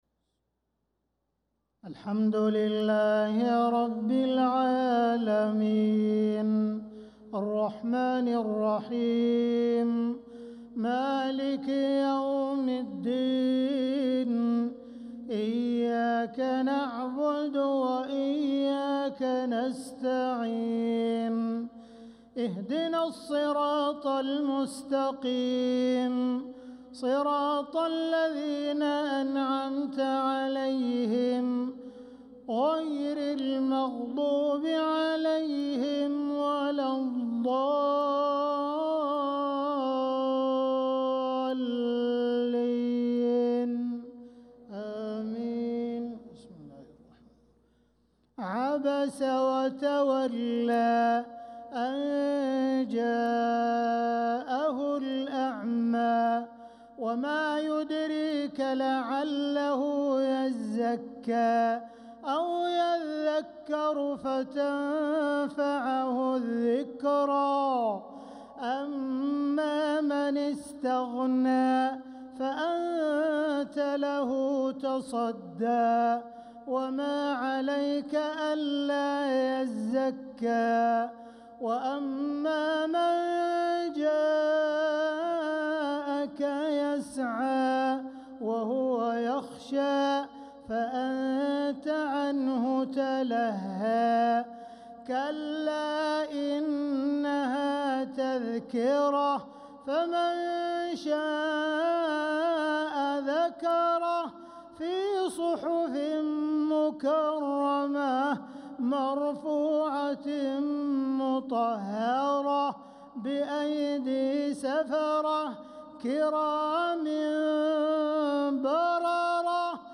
صلاة العشاء للقارئ عبدالرحمن السديس 16 محرم 1446 هـ
تِلَاوَات الْحَرَمَيْن .